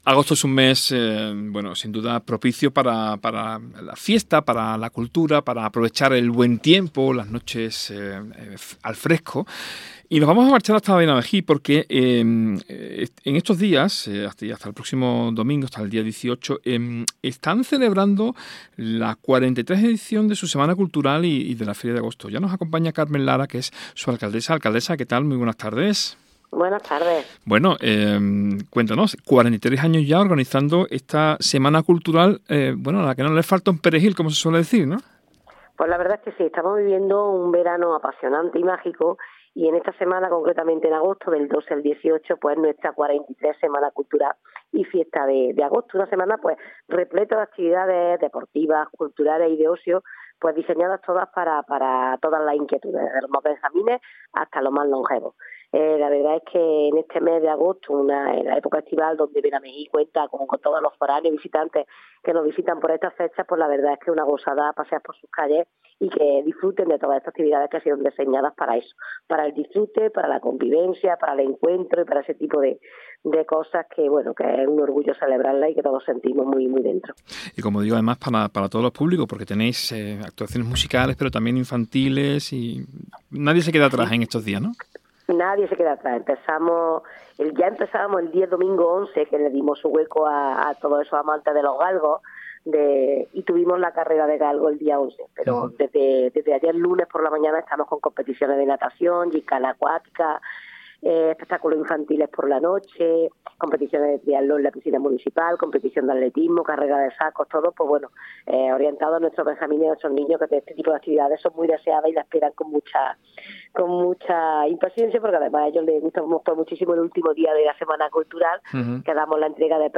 Hablamos con su alcaldesa, Carmen Lara, en Hoy por Hoy de Verano